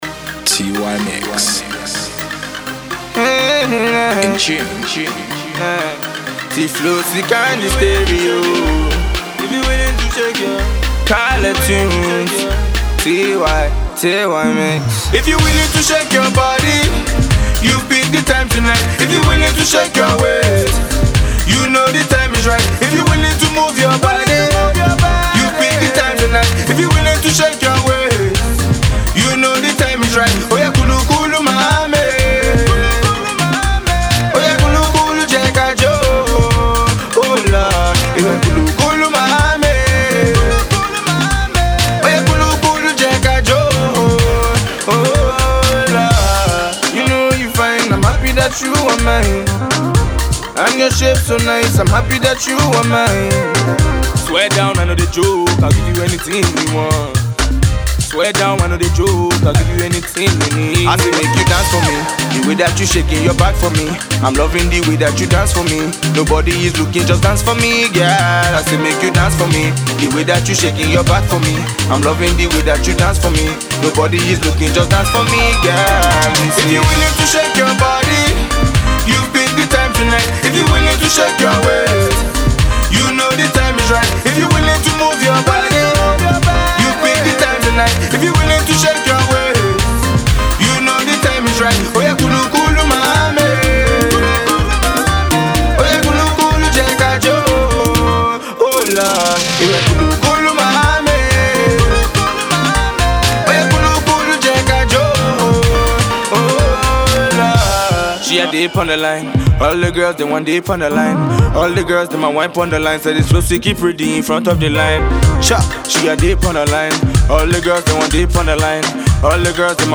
Soukous